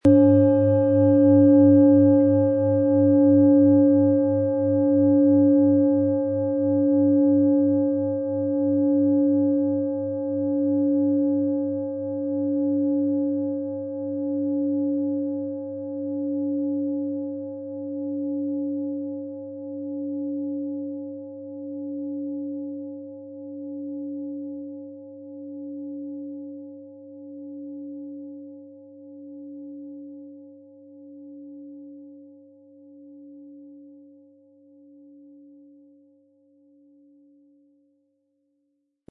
Planetenschale® Sei spontan und unbefangen & neue Ideen bekommen mit Uranus-Ton, Ø 25,7 cm, 1700-1800 Gramm, inkl. Klöppel - Anreiben möglich
Unter dem Artikel-Bild finden Sie den Original-Klang dieser Schale im Audio-Player - Jetzt reinhören.
Ein die Schale gut klingend lassender Schlegel liegt kostenfrei bei, er lässt die Planetenklangschale Uranus harmonisch und angenehm ertönen.
PlanetentonUranus
MaterialBronze